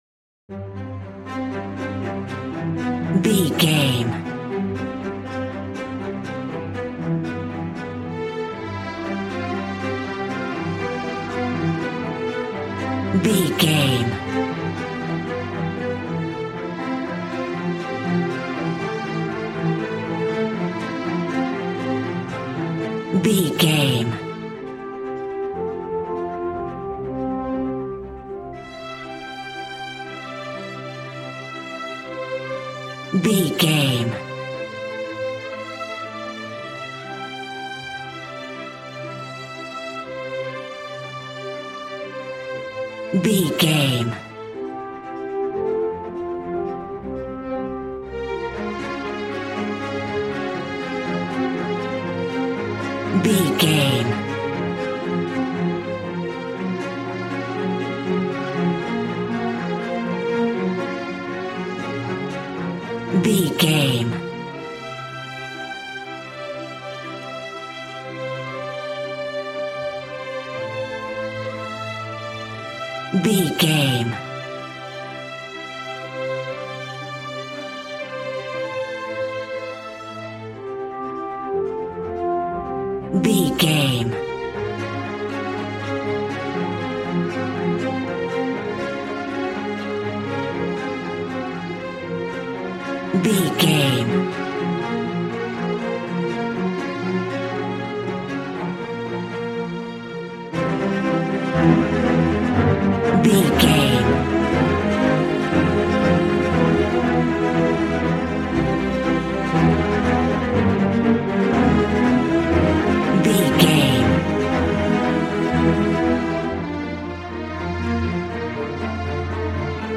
A classical music mood from the orchestra.
Regal and romantic, a classy piece of classical music.
Ionian/Major
regal
cello
violin
strings